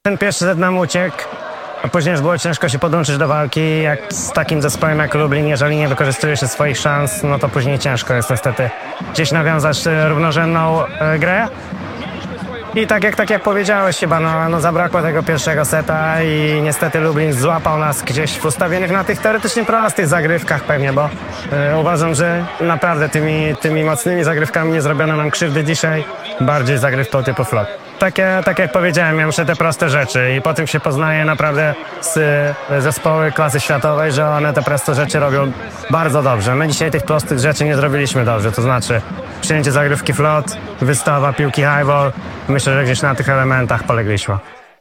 Pomeczowy komentarz